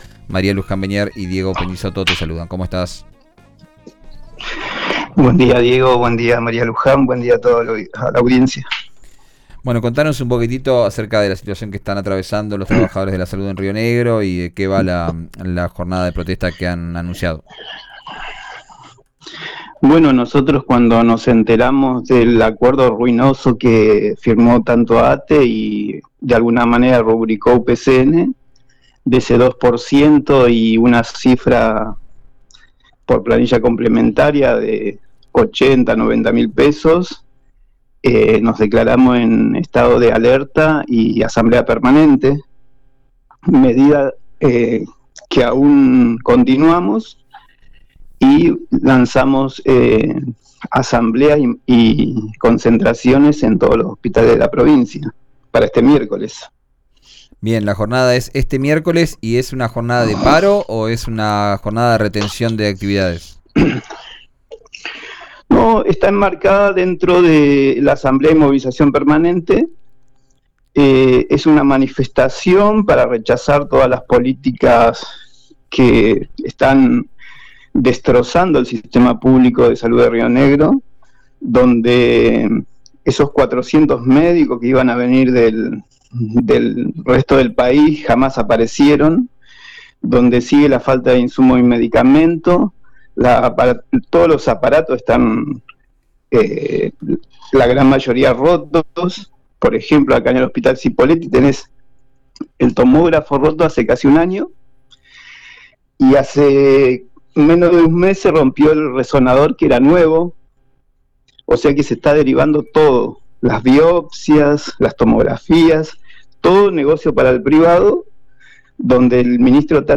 en el aire de »Vos al aire» por RÍO NEGRO RADIO: